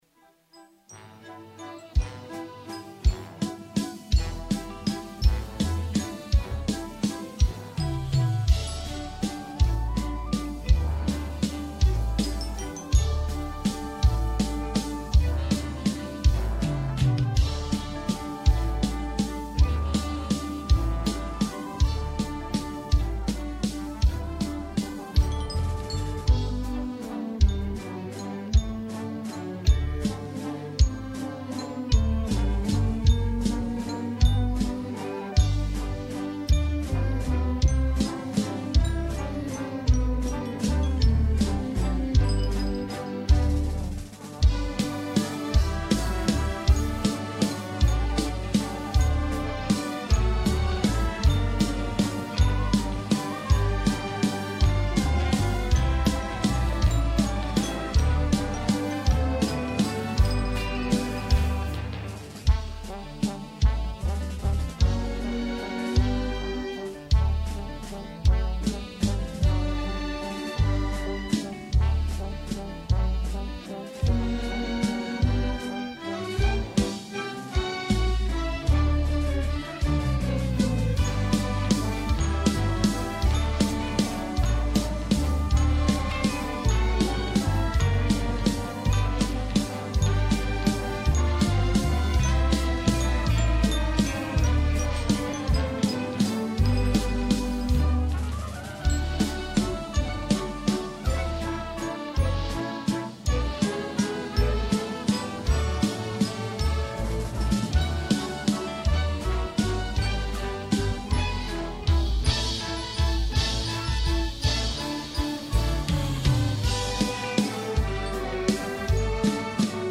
No Lyrics…